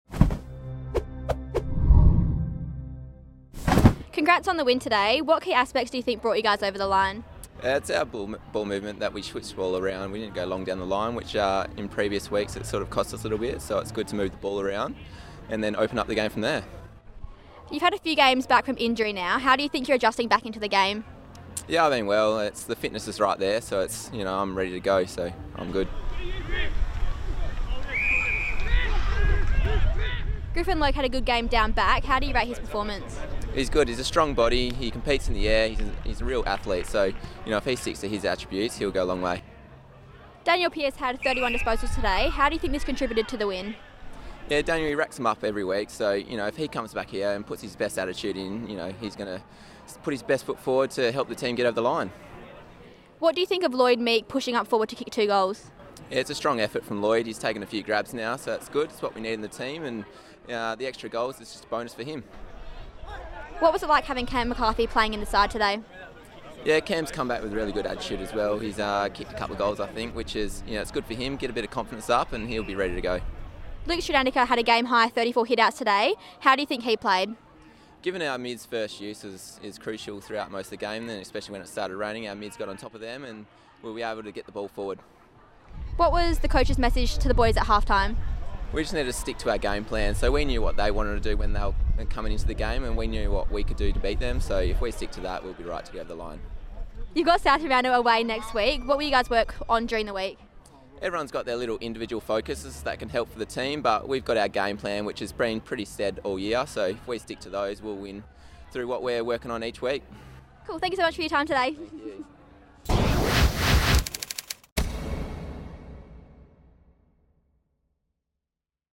WAFL Interview - Rnd 10 v West Perth